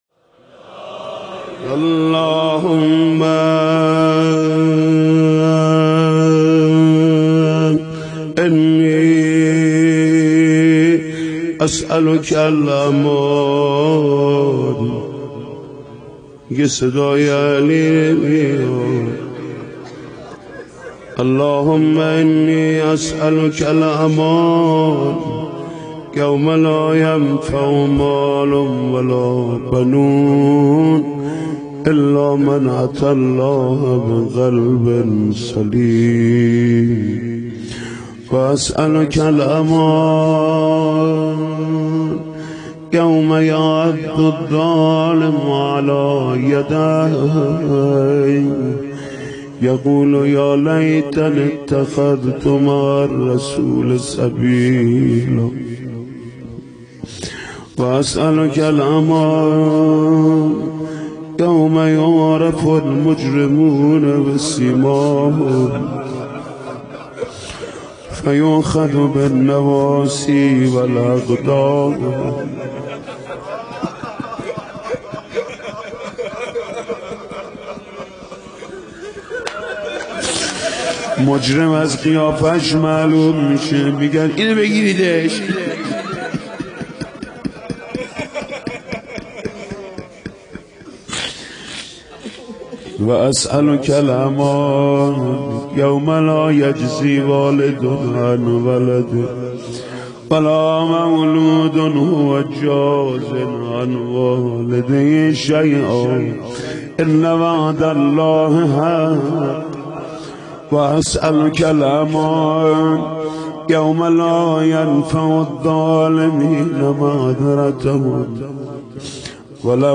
در فصل پنجم از باب زیارات کتاب شریف مفاتیح الجنان شیخ عباس قمی رحمه الله در اعمال مسجد کوفه، مناجات امیرالمومنین علیه السلام در مسجد کوفه را با نوای دلنشین حاج محمود کریمی خدمت شما عزیزان تقدیم می کنیم: